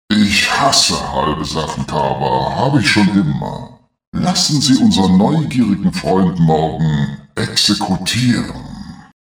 Zod himself shouts out orders while wearing a wide-brimmed hat left over from the American civil war and puffing his cigar, his first officer Lassar comes up with appropriate regulations for all occasions and the entire cast are a set of elaborately thought-out characters; each and every one of them has a unique style which fits in wonderfully to an ingenious collection of humorous dialogues.
picture x Captain Zod: